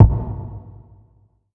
Diced Up Kick.wav